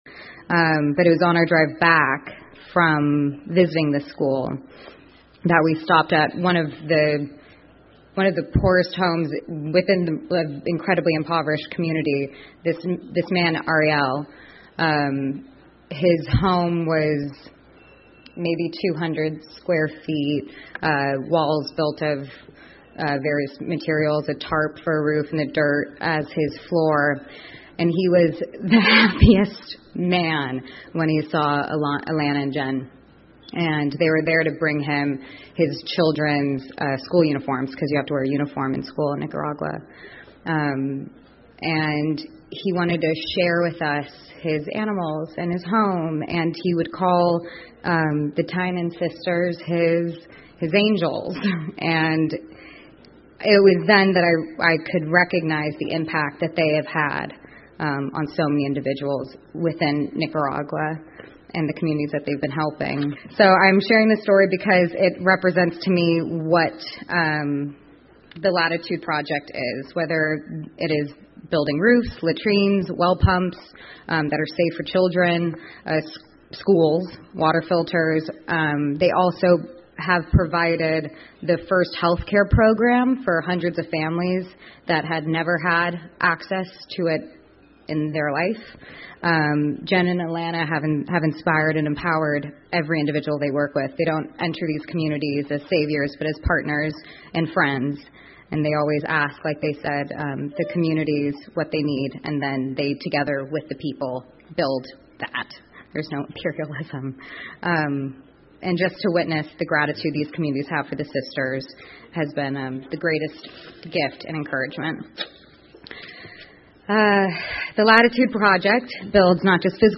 英文演讲录 伊丽莎白·奥尔森：获得艾玛未来奖的演讲(2) 听力文件下载—在线英语听力室